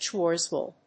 Schwarzwald.mp3